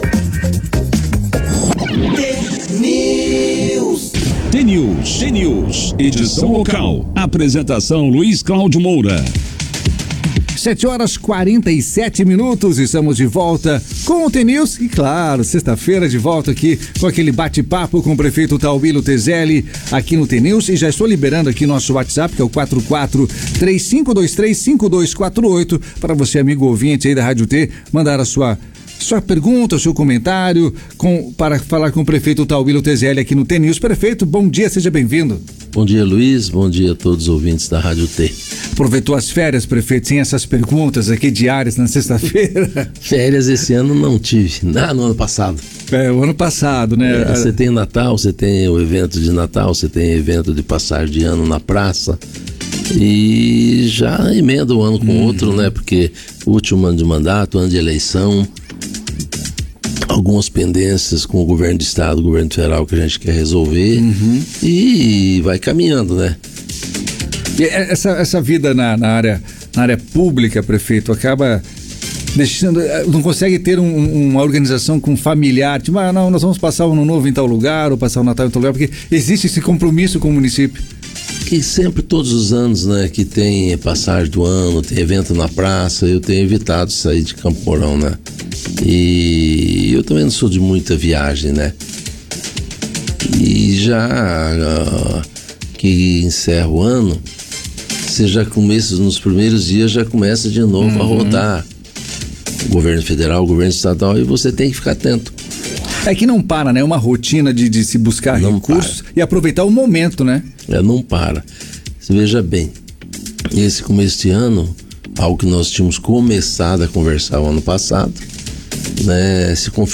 Prefeito Tauillo na Rádio T FM. Ouça a primeira entrevista do ano
Nesta sexta-feira, dia 26, ocorreu a primeira participação do atual prefeito de Campo Mourão, Tauillo Tezelli, no jornal T News da Rádio T FM.